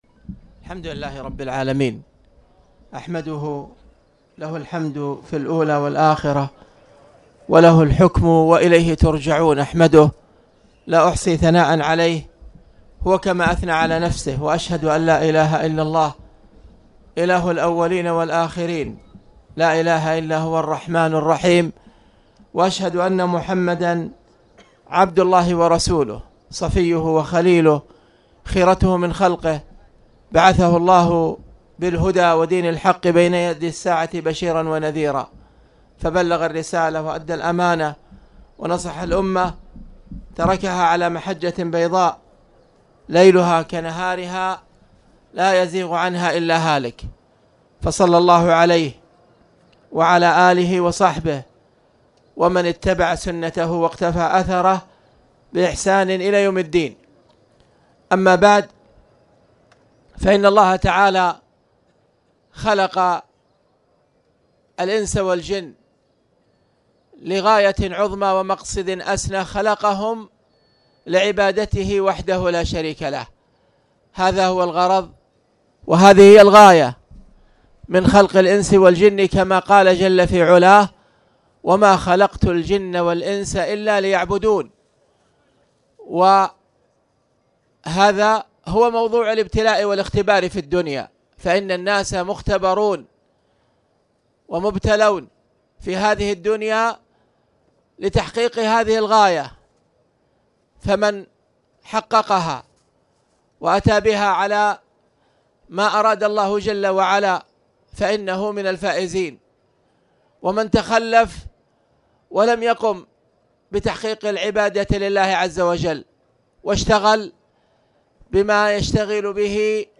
تاريخ النشر ٦ جمادى الآخرة ١٤٣٨ هـ المكان: المسجد الحرام الشيخ